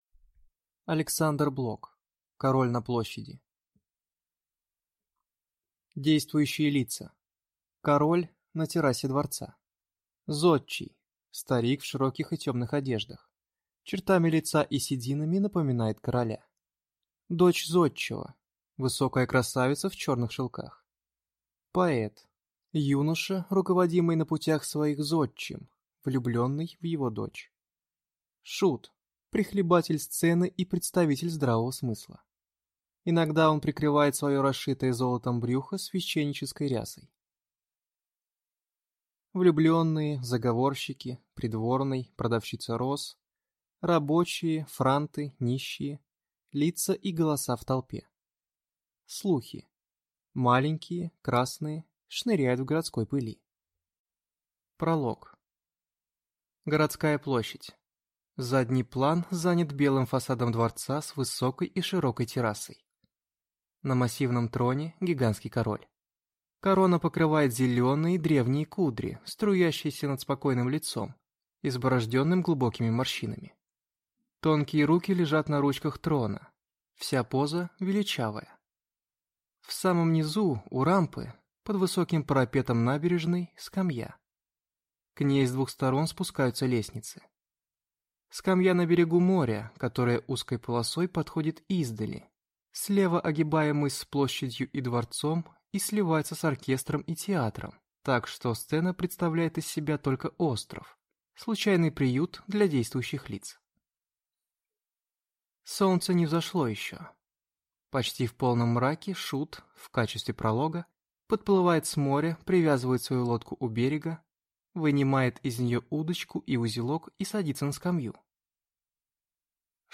Аудиокнига Король на площади | Библиотека аудиокниг